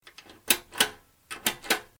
关于拉式开灯音效的PPT演示合集_风云办公